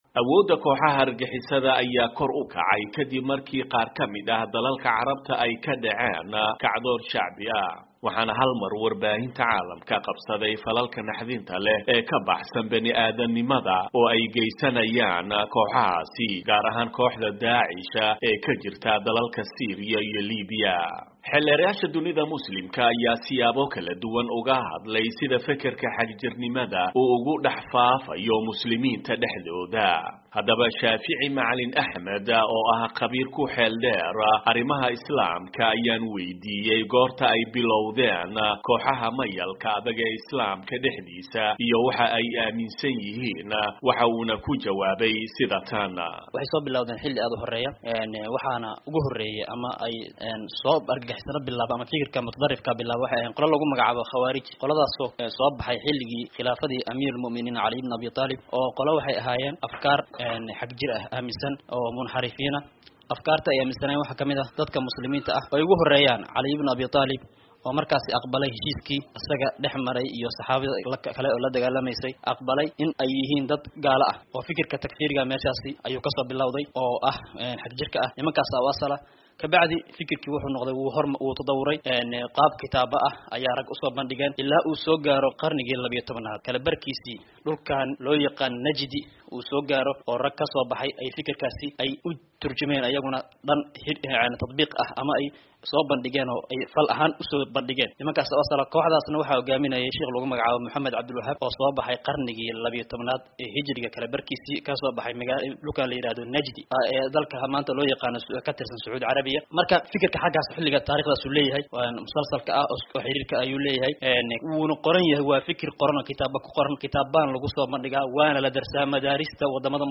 Warbixin: Arrimaha Saldhiga u ah Xagjirnimada